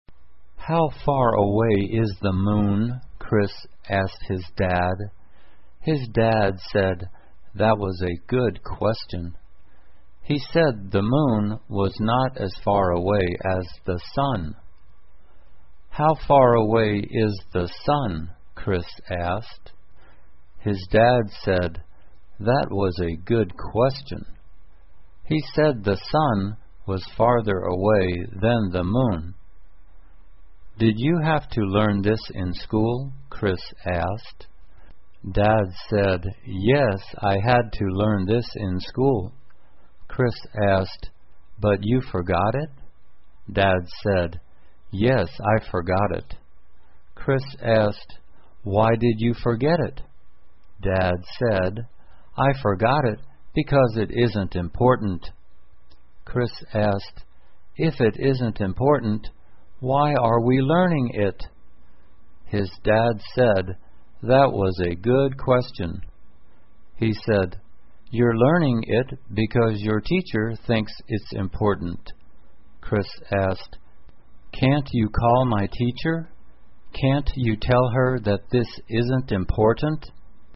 慢速英语短文听力 月球和太阳 听力文件下载—在线英语听力室